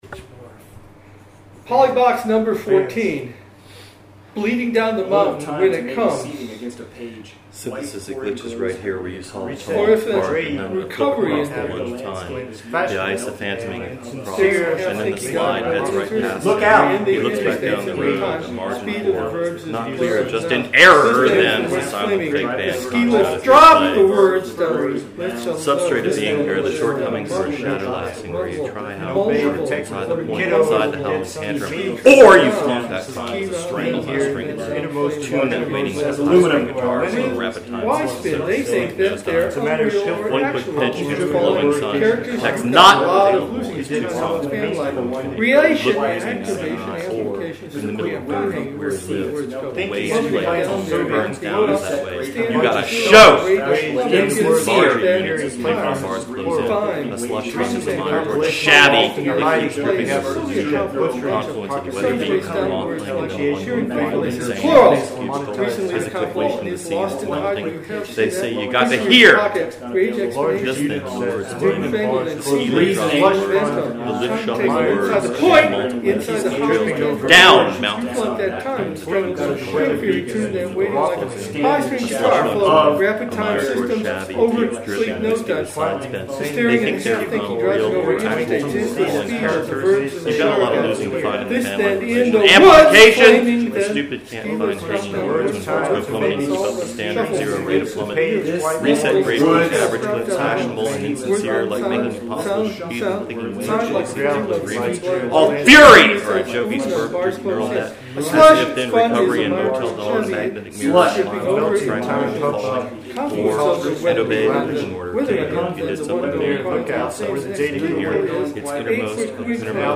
May 11, 2011 Meeting Recordings
A set of polyphons.